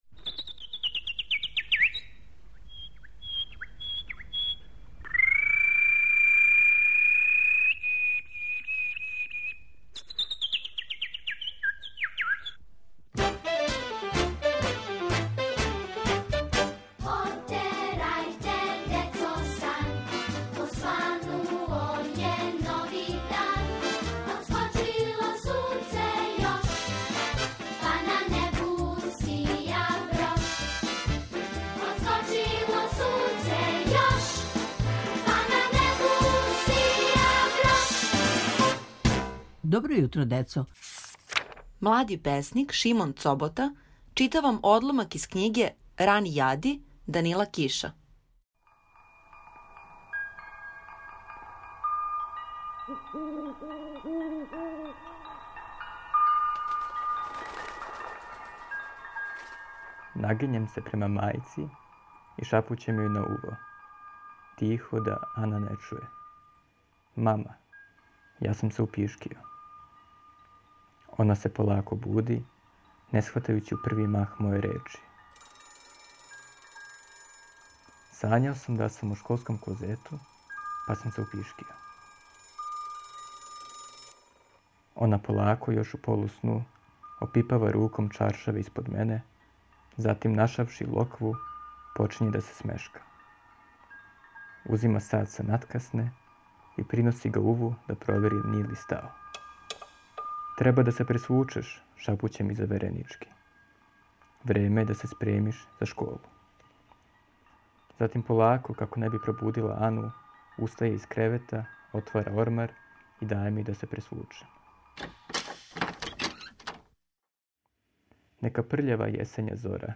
Слушате одломак из књиге "Рани јади" Данила Киша